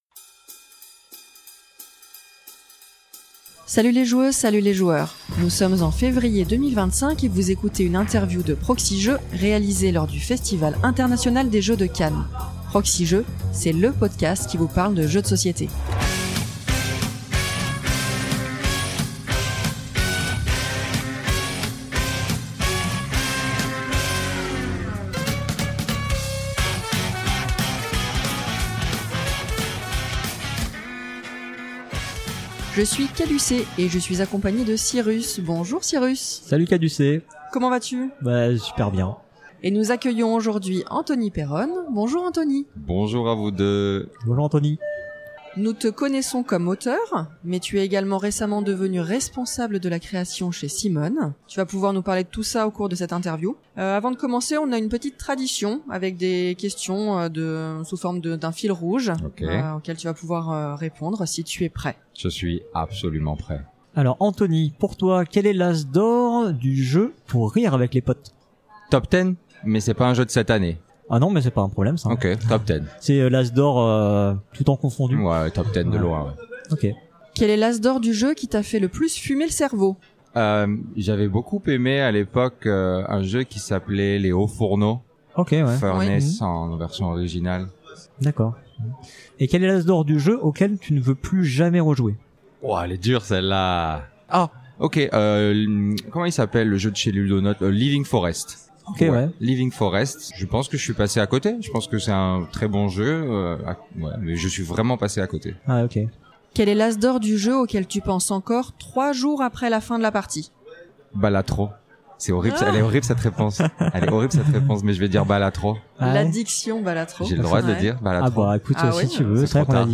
Lors de son séjour au Festival International des Jeux de Cannes, la Proxi-Team a rencontré de nombreuses actrices et de nombreux acteurs du monde du jeu de société.